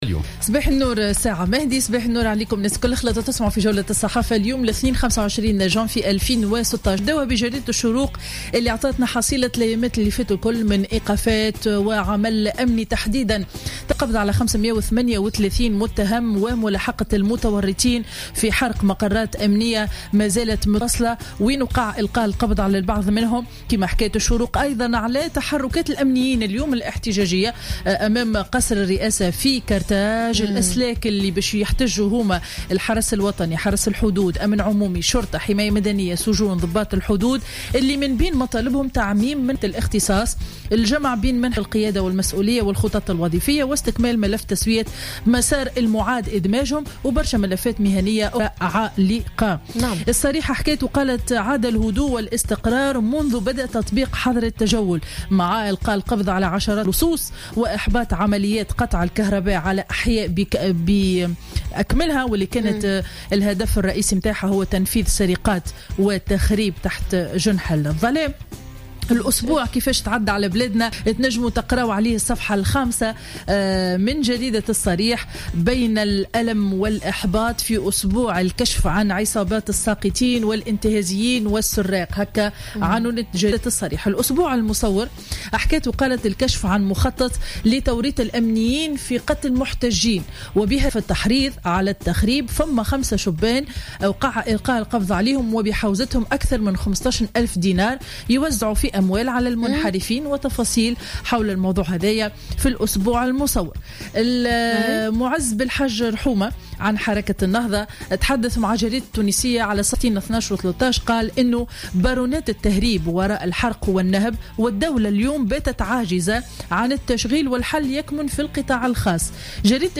Revue de presse du lundi 25 janvier 2016